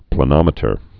(plə-nŏmĭ-tər, plā-)